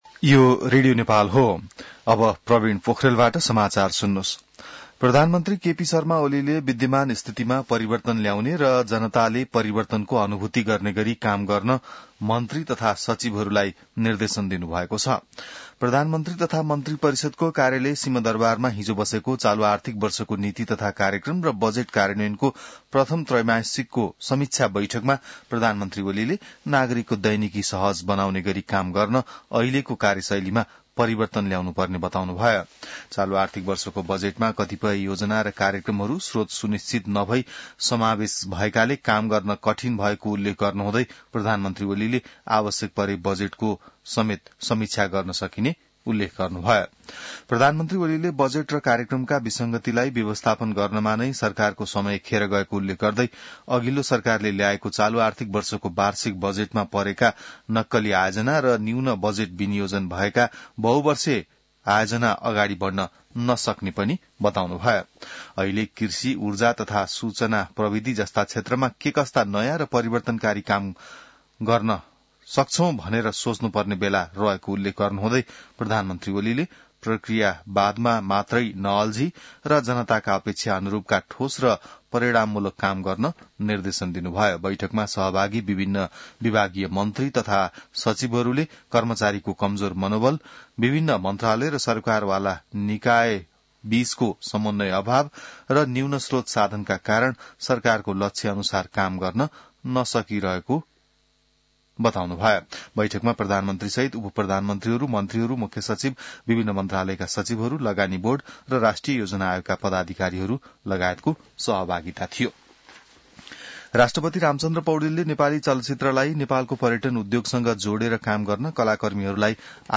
बिहान ६ बजेको नेपाली समाचार : १४ मंसिर , २०८१